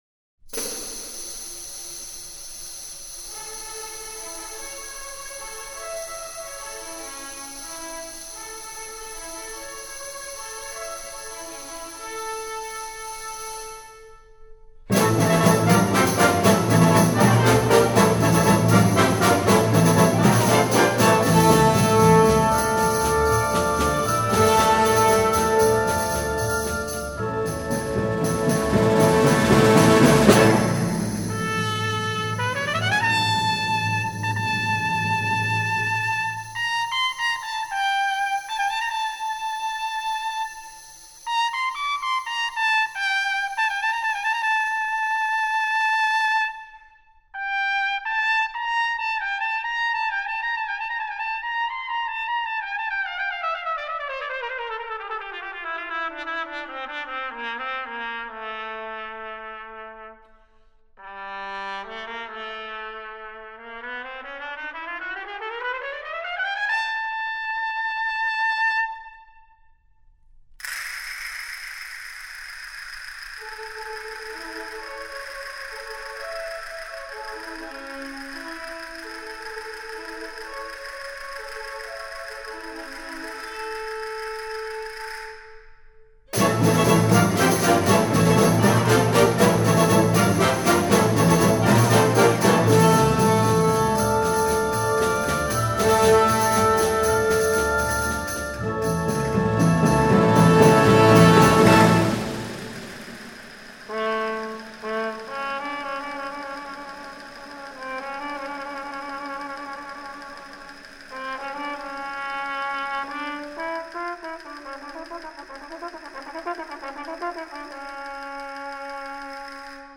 for Two Solo Trumpets with Wind Ensemble